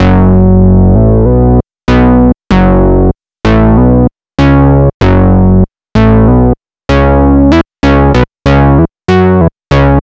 Track 14 - Synth Bass 03.wav